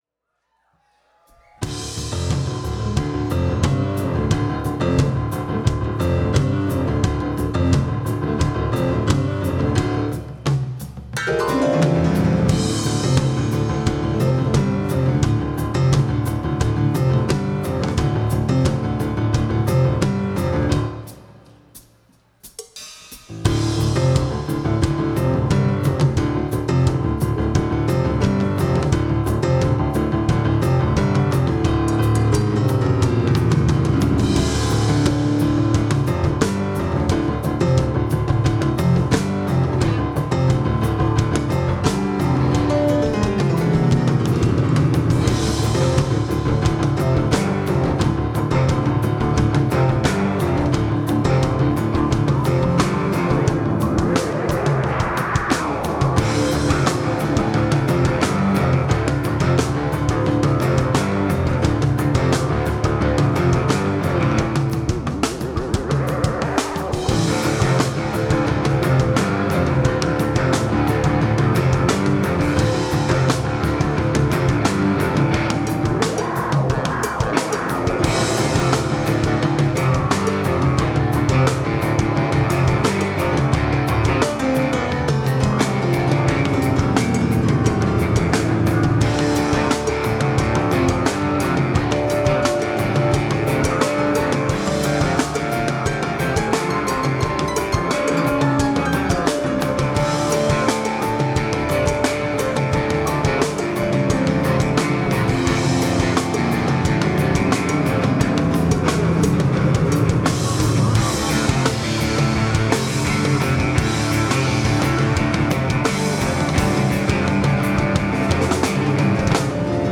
Welcome back for set #2 of this amazing Jam band.
Keys / Vox
Guitar / Vox
Bass / Vox
Drums / Vox Source: SBD Matrix